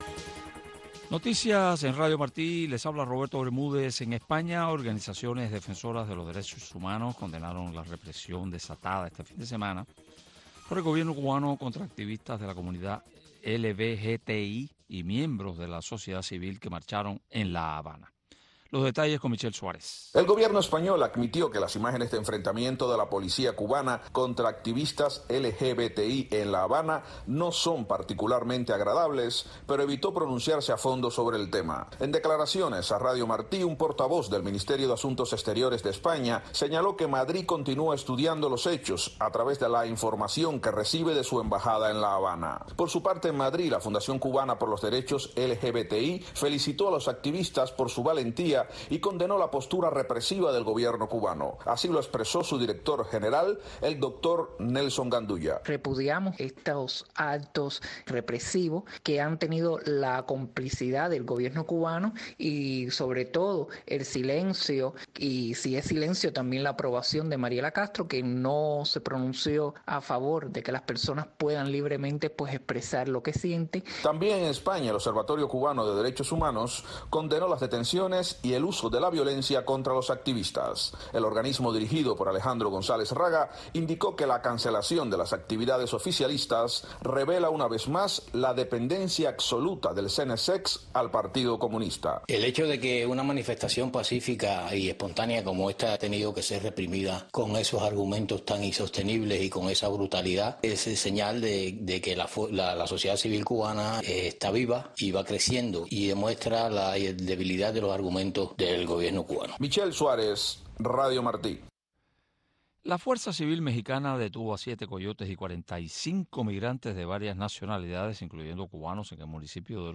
“Ventana a Miami”, un programa conducido por el director de la oficina de transmisiones hacia Cuba, Tomás Regalado, te invita a sintonizarnos de lunes a viernes a la 1:30 PM en Radio Martí. “Ventana a Miami” te presenta la historia de los cubanos que se han destacado en el exilio para que tú los conozcas.